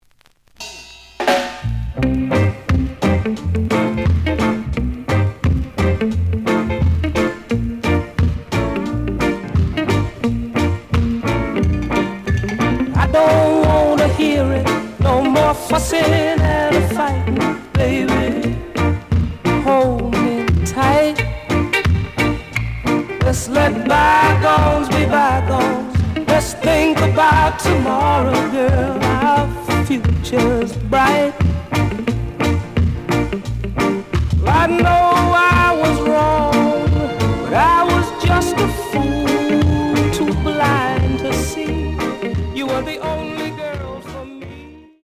The audio sample is recorded from the actual item.
●Format: 7 inch
●Genre: Rock Steady